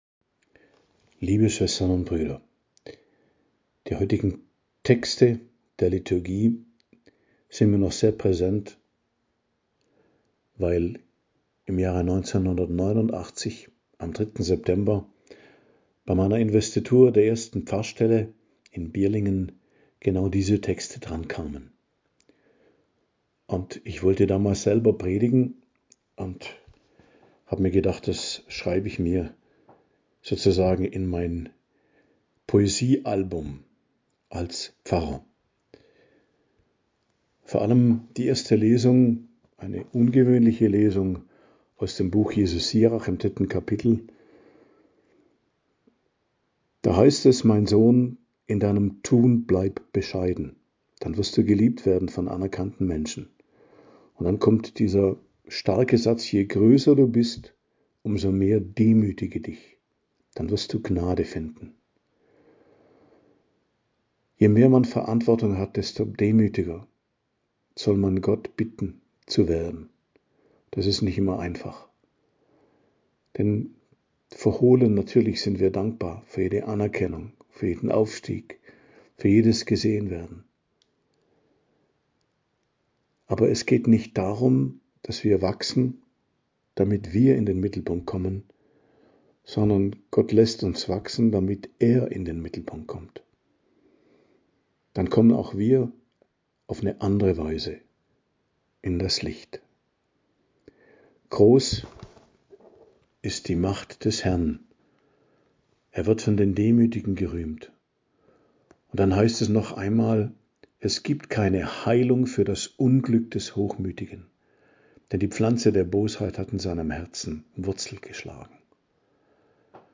Predigt zum 22. Sonntag i.J., 31.08.2025